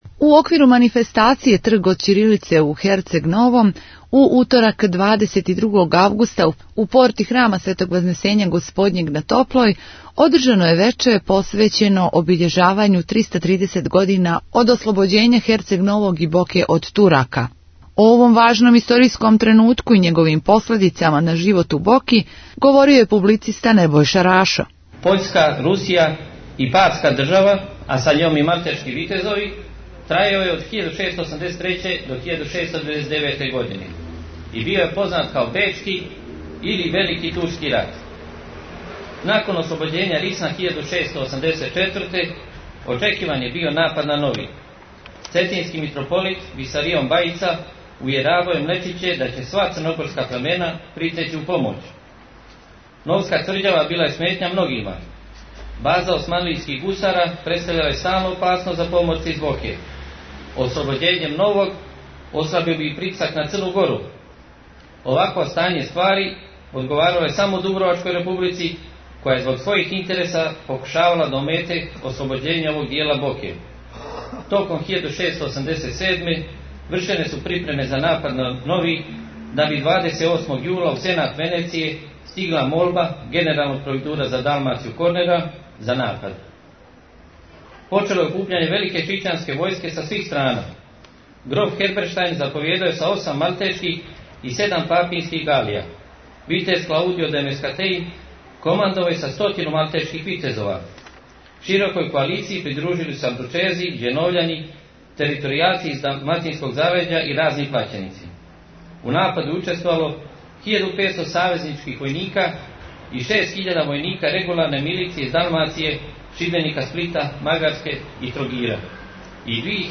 У оквиру „Трга од ћирилице" у Херцег Новом, 22. августа у порти цркве Светог Вазнесења Господњег на Топлој, одржано је Вече посвећено обиљежавању 330 година од ослобођења Херцег Новог и Боке од Турака.